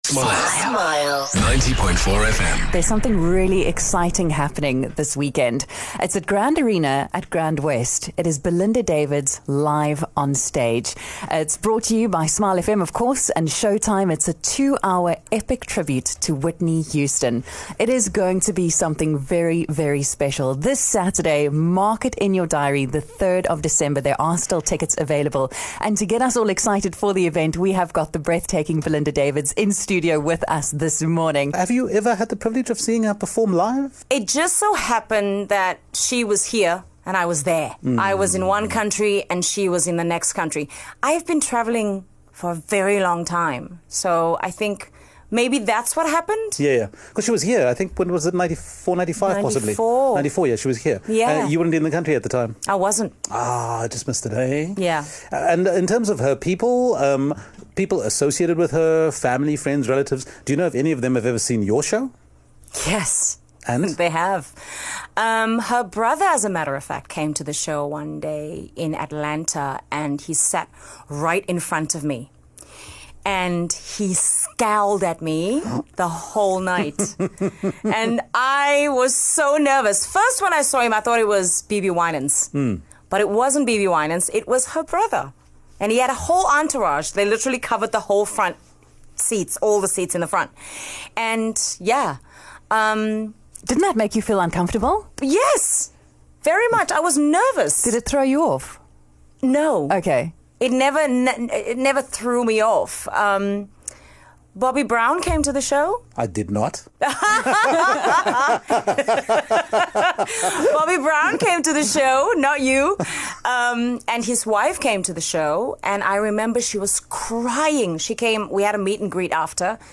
joined Smile Breakfast in studio ahead of her performance